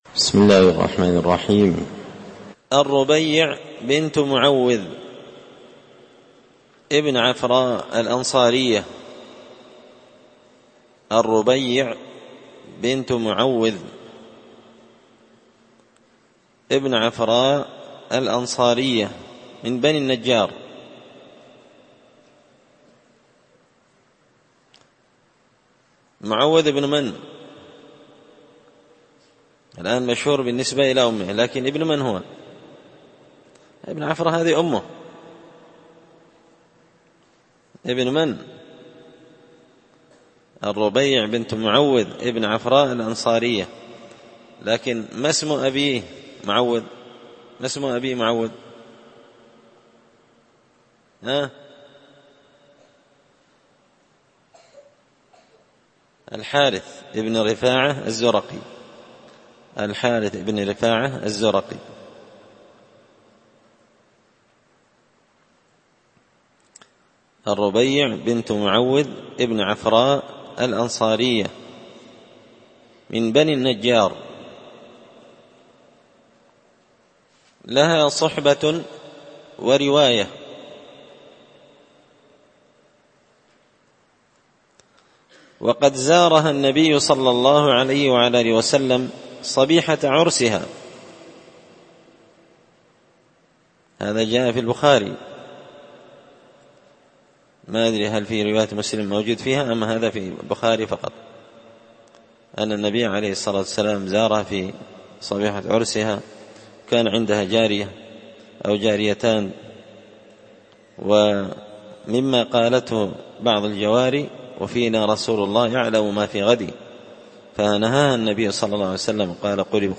الدرس 194 الربيع بنت معوذ – قراءة تراجم من تهذيب سير أعلام النبلاء
مسجد الفرقان